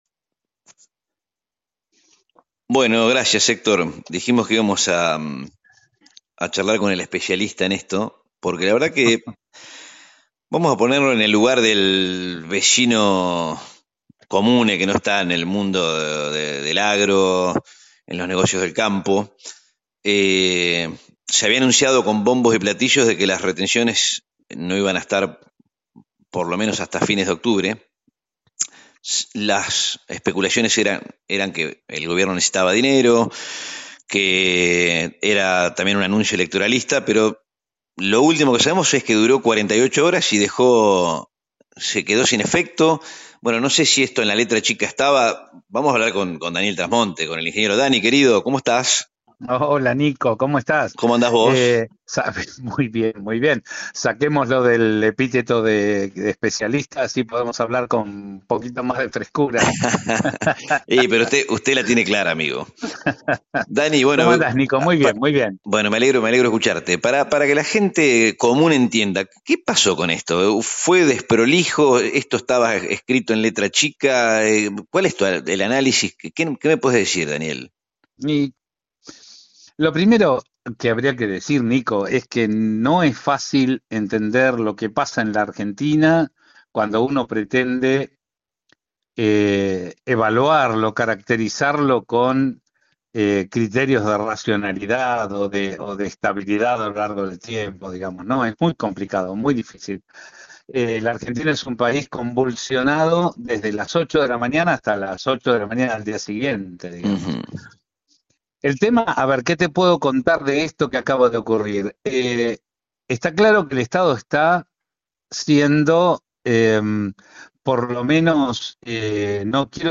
En esta entrevista radial en Magazine Café por FM 104.1